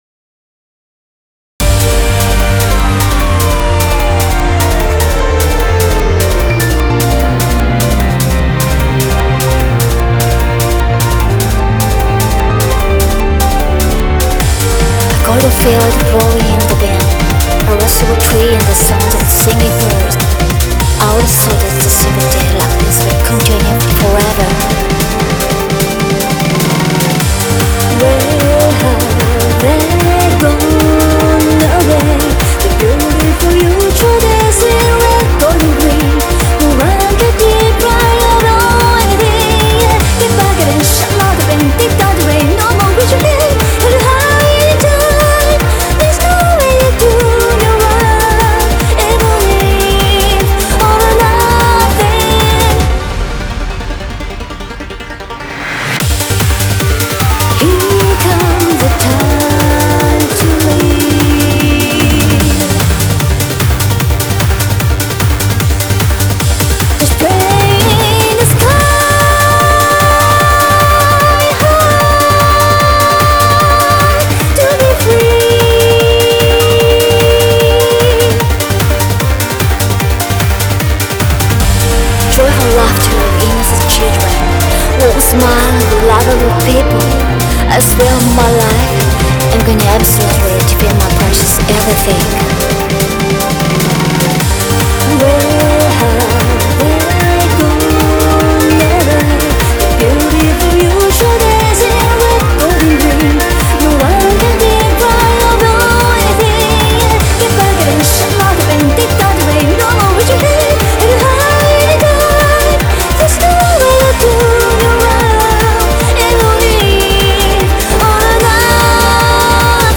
BPM150
- OST audio